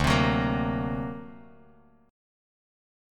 D+7 Chord
Listen to D+7 strummed